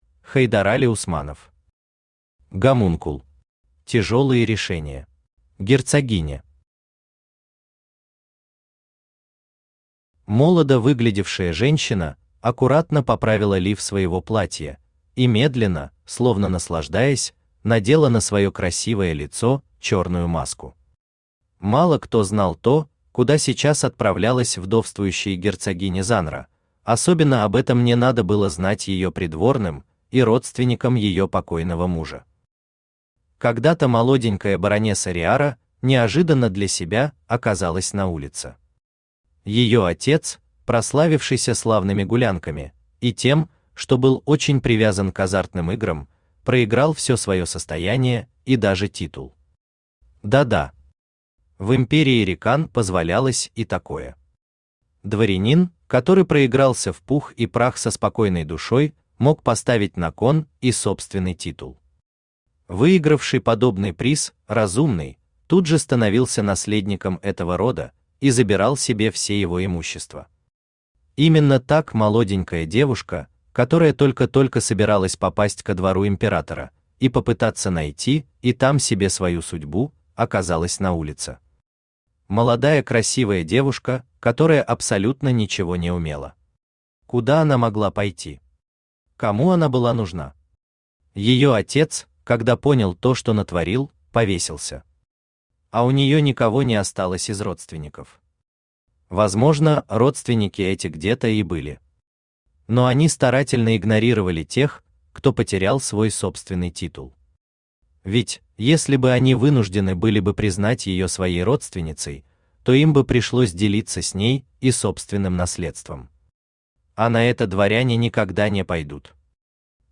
Аудиокнига Гомункул. Тяжёлые решения | Библиотека аудиокниг
Тяжёлые решения Автор Хайдарали Усманов Читает аудиокнигу Авточтец ЛитРес.